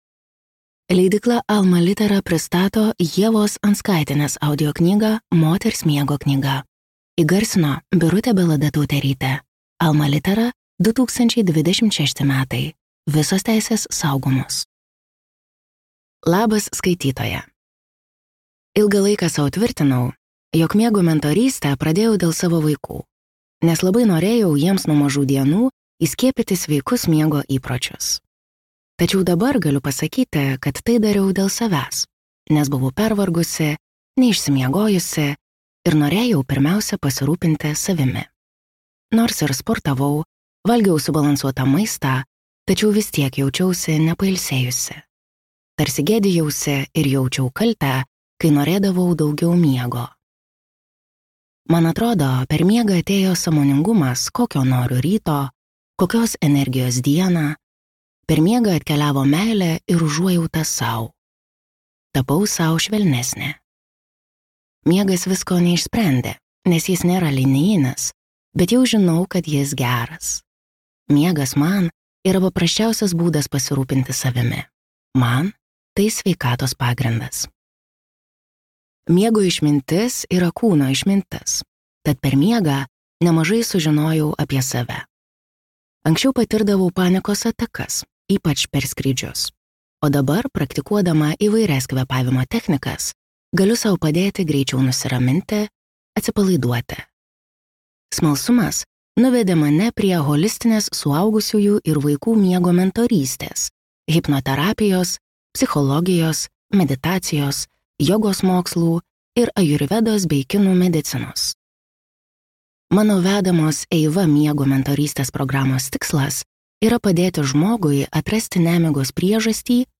Moters miego knyga | Audioknygos | baltos lankos